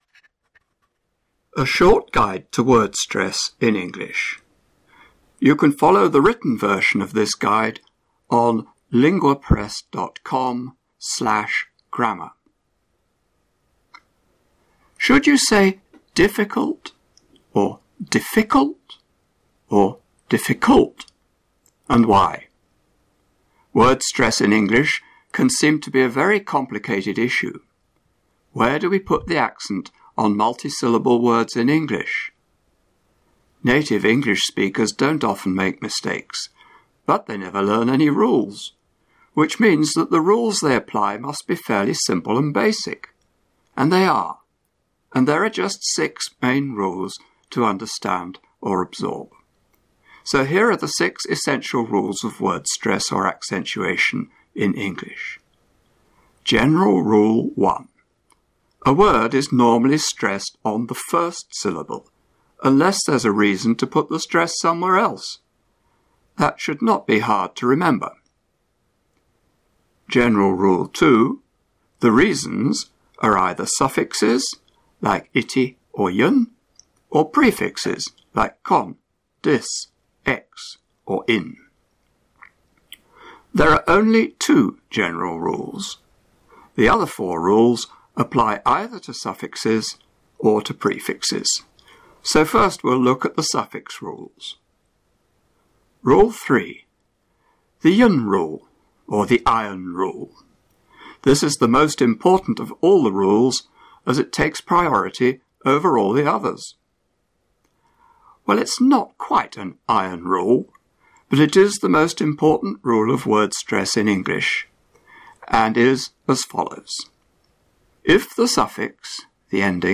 Full audio recording of the word stress rules and examples.